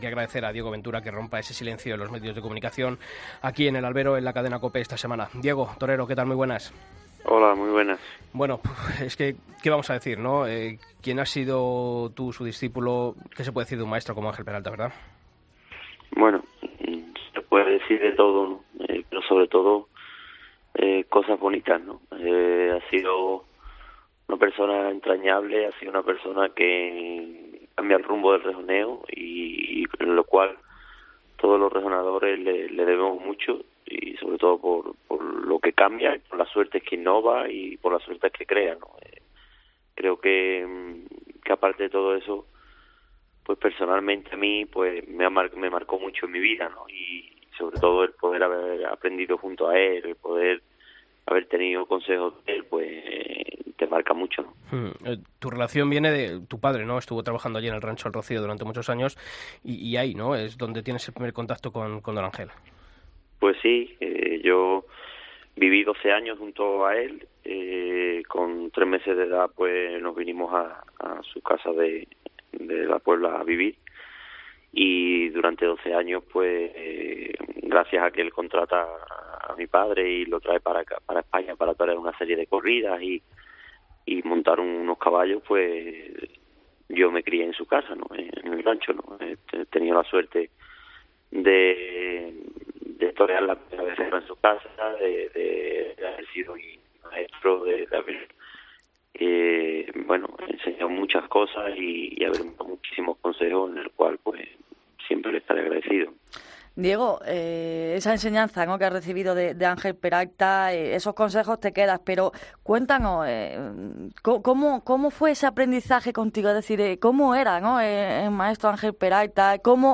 Escucha la entrevista a Diego Ventura en El Albero